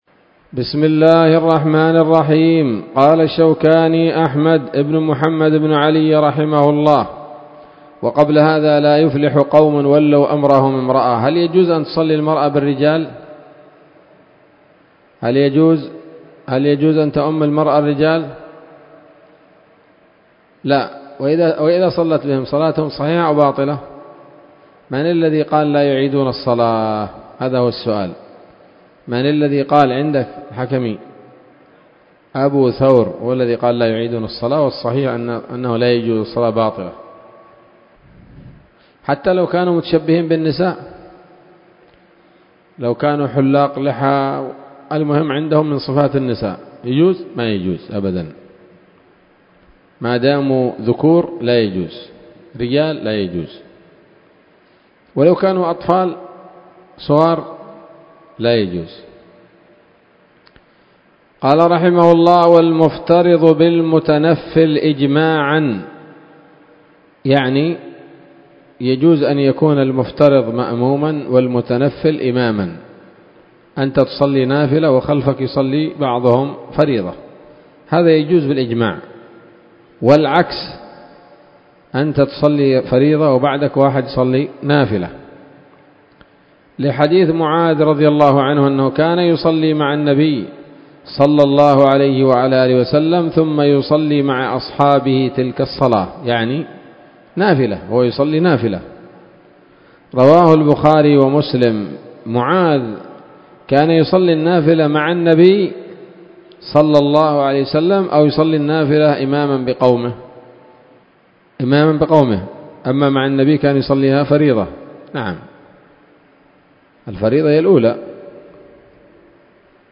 الدرس السادس والعشرون من كتاب الصلاة من السموط الذهبية الحاوية للدرر البهية